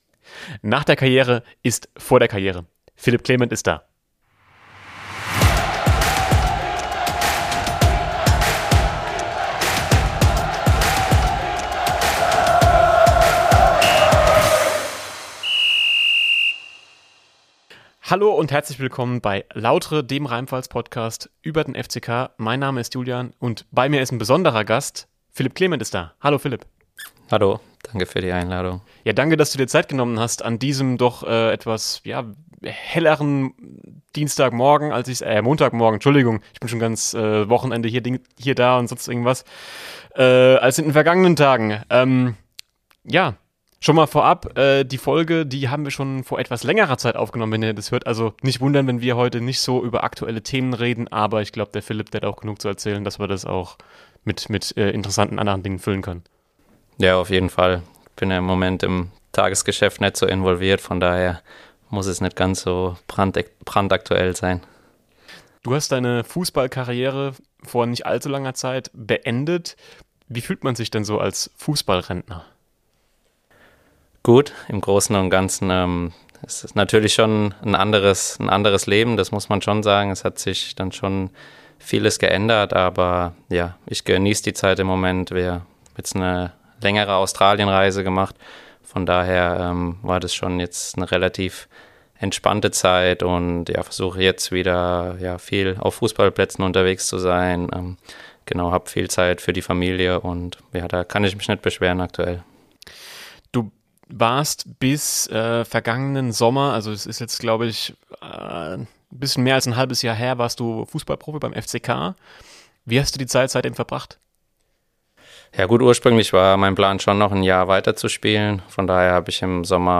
den FCK und die Karriere nach der Karriere ~ Lautre - Der FCK-Podcast Podcast